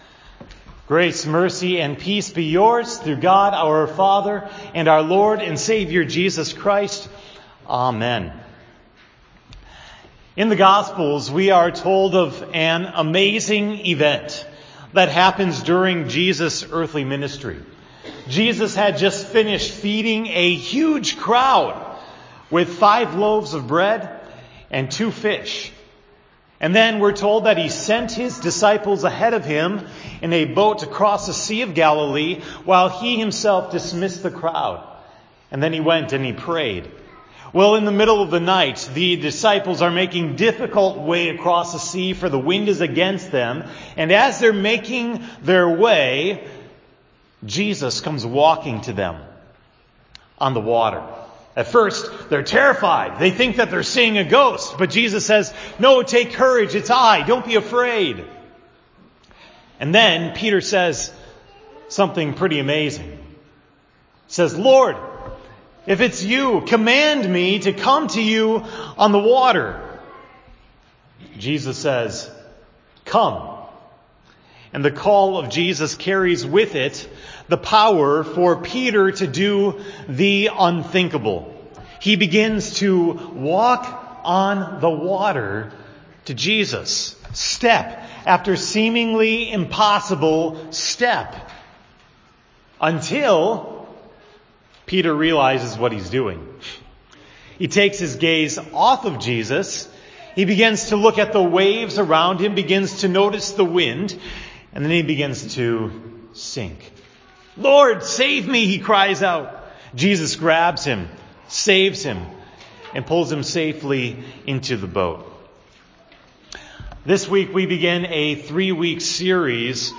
The sermon for September 9, 2018 at Hope Text: John 15:1-11